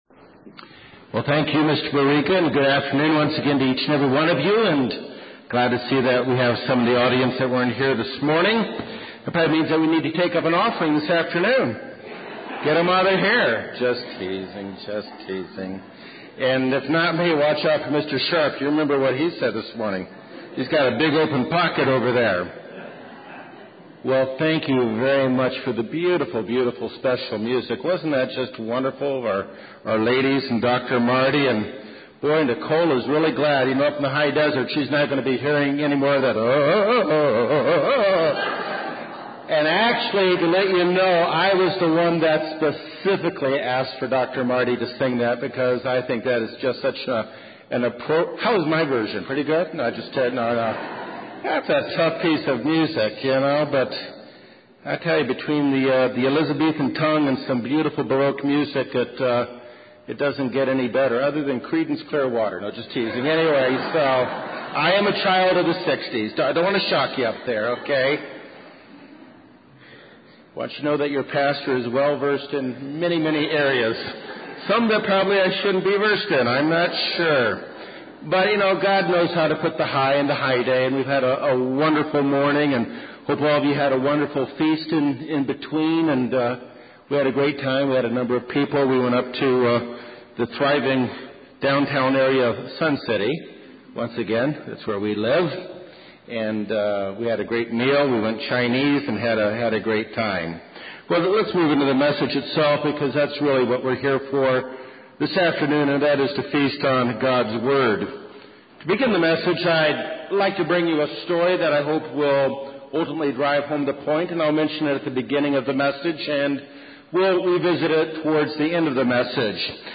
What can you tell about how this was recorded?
This message was given on the Feast of Trumpets.